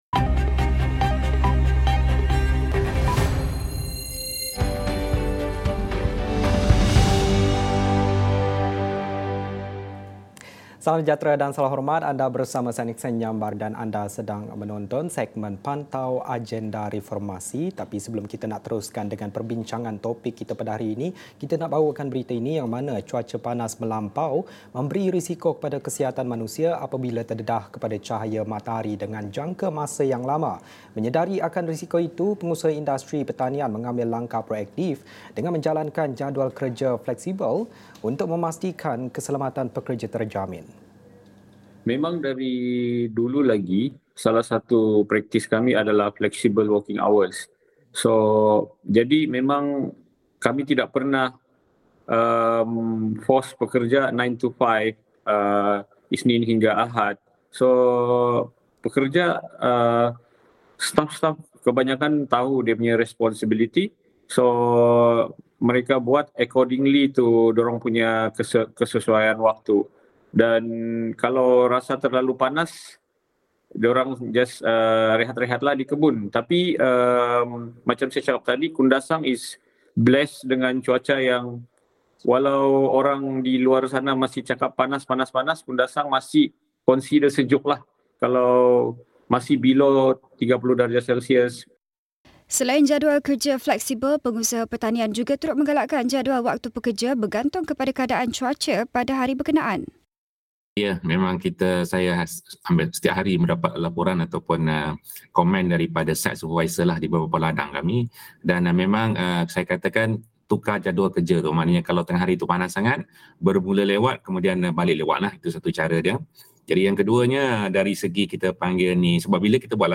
Menyedari akan risiko itu, pengusaha industri pertanian mengambil langkah proaktif dengan menjalankan jadual kerja fleksibel untuk memastikan keselamatan pekerja terjamin. Ikuti diskusi dalam Pantau Agenda Reformasi.